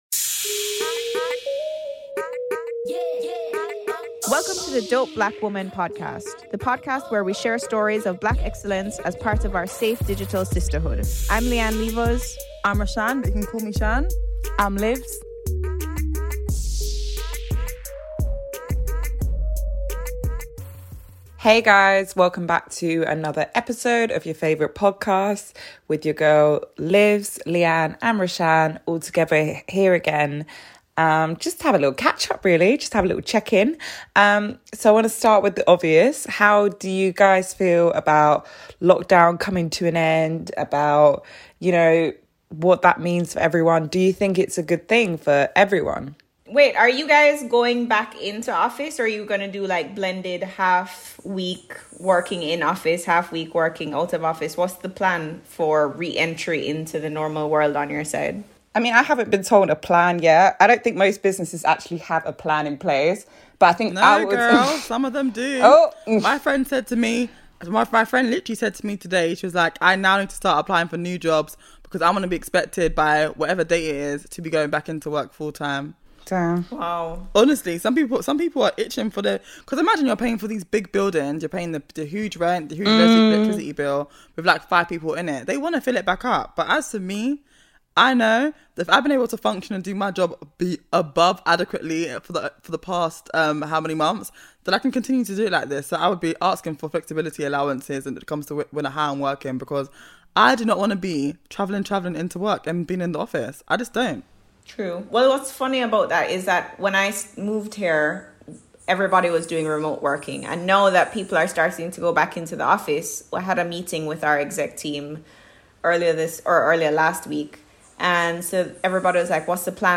This week your favourite trio catch up to talk about the UK's lockdown coming to an end, self-development and to pee or not pee on the seat.